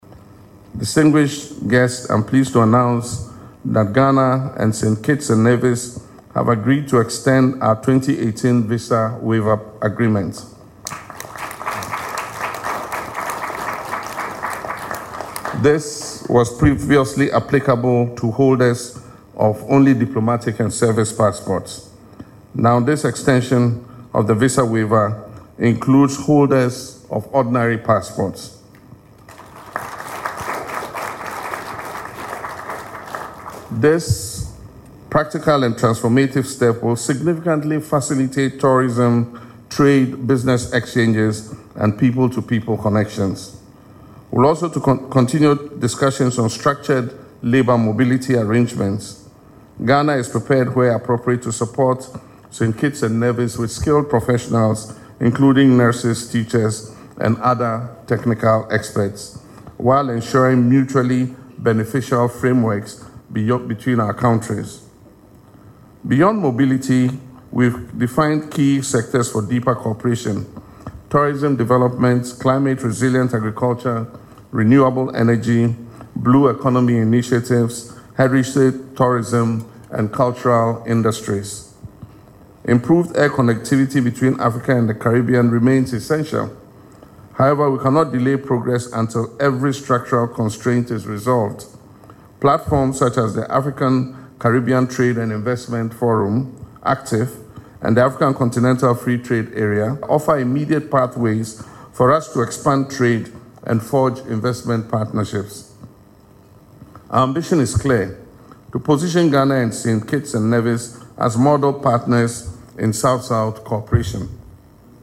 President Mahama made the announcement at an Official State Luncheon held in honour of the Prime Minister of Saint Kitts and Nevis, Terrance Michael Drew, who is in Ghana for a state visit.
LISTEN TO PRESIDENT MAHAMA IN THE AUDIO BELOW: